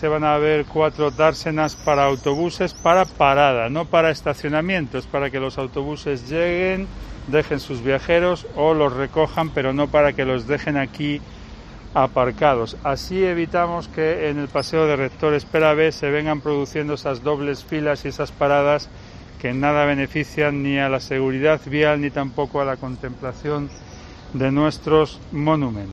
El alcalde de Salamanca Carlos García Carbayo explica las obras en la Plaza del Mercado Viejo